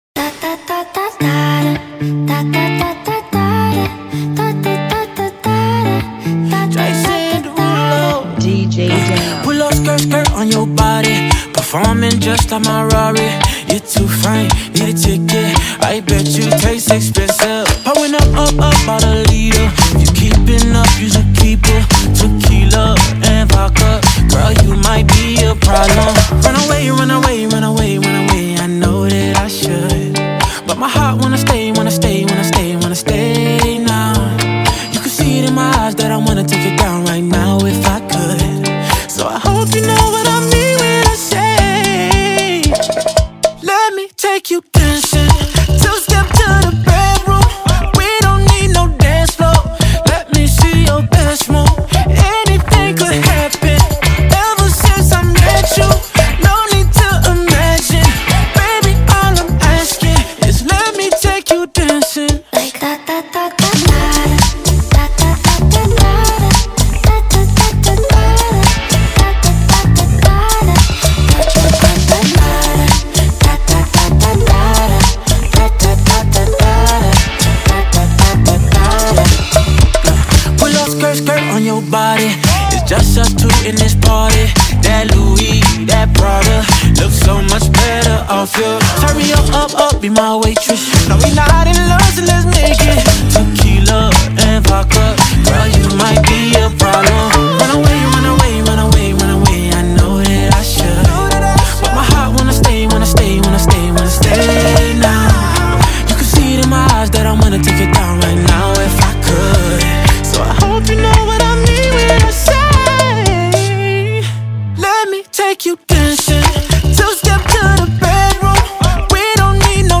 113 BPM
Genre: Bachata Remix